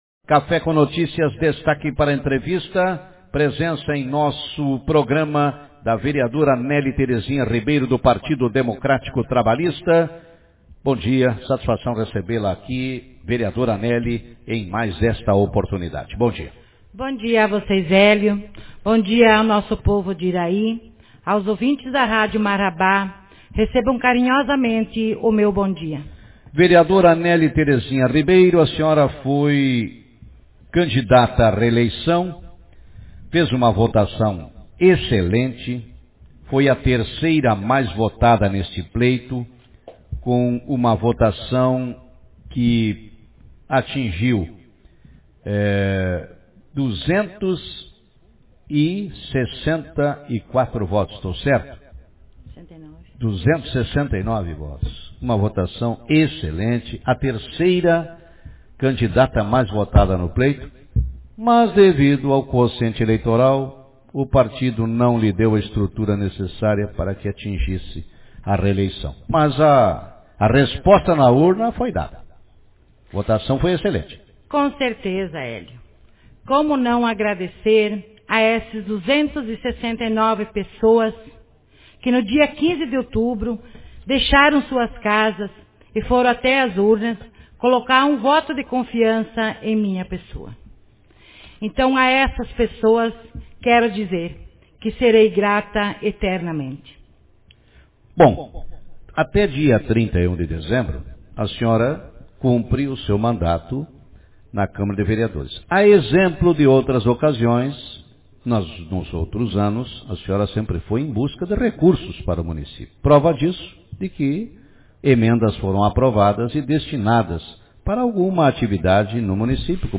Vereadora Néli Ribeiro avalia o pleito eleitoral de 15 de novembro, em Iraí Autor: Rádio Marabá 07/12/2020 0 Comentários Manchete Na manhã de hoje no programa Café com Notícias, a vereadora Néli Terezinha Ribeiro, PDT, concedeu entrevista e avaliou seu trabalho nesta legislatura e também falou sobre o pleito eleitoral de 15 de novembro, em Iraí.